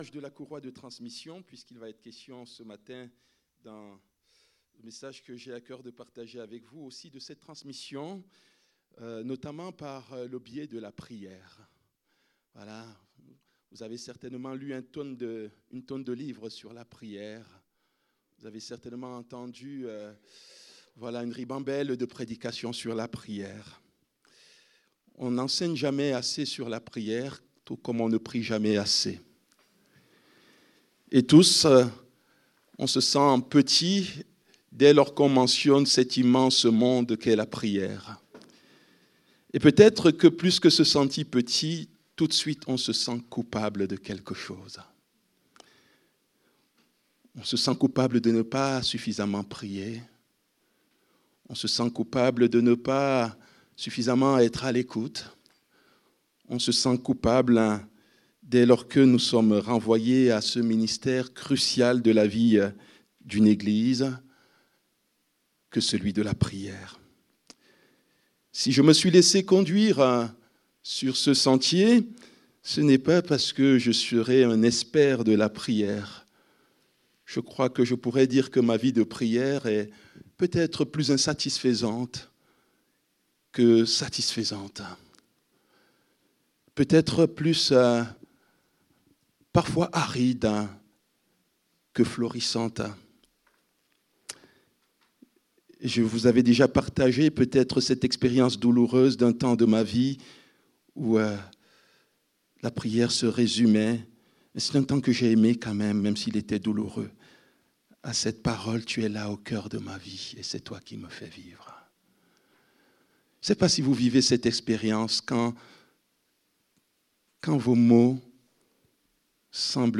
Culte du dimanche 01 mars 2026, prédication